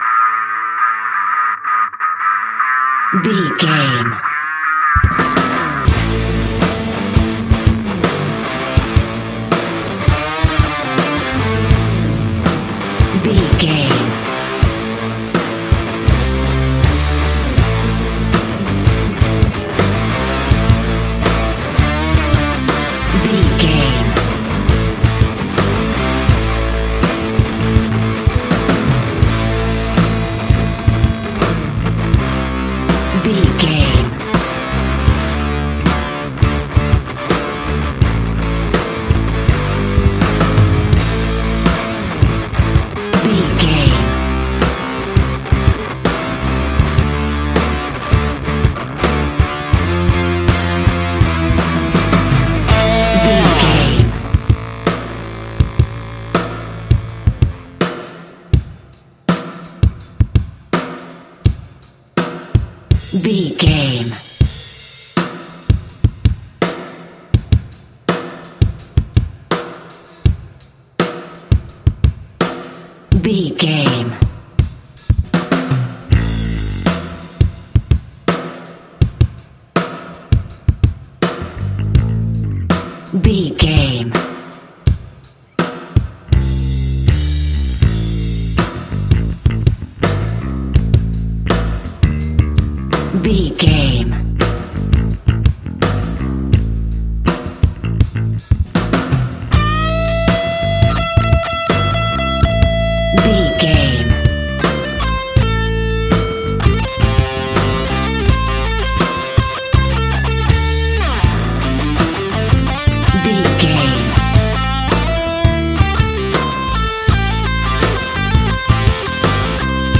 Ionian/Major
A♭
guitars
hard rock
heavy rock
distortion
instrumentals